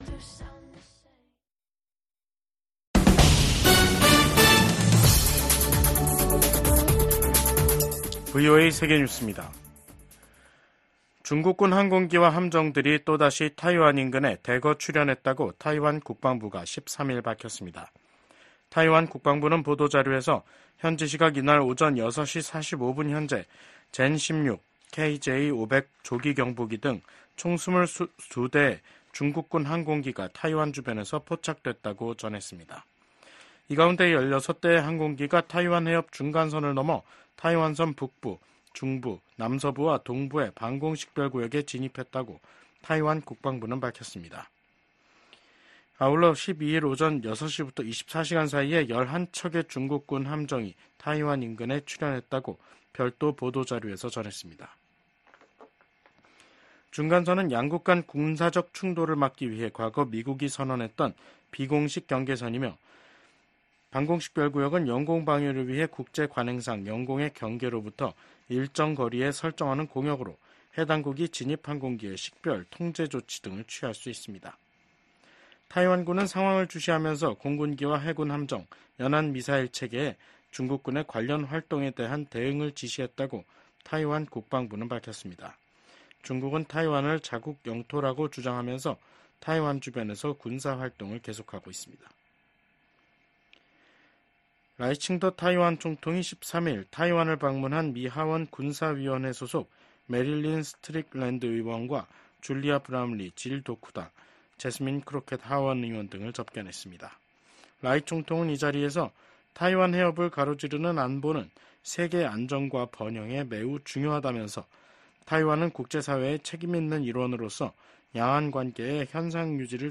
VOA 한국어 간판 뉴스 프로그램 '뉴스 투데이', 2024년 8월 13일 3부 방송입니다. 북러 군사 밀착이 우크라이나뿐 아니라 인도태평양 지역 안보에도 영향을 미칠 것이라고 미국 백악관이 지적했습니다.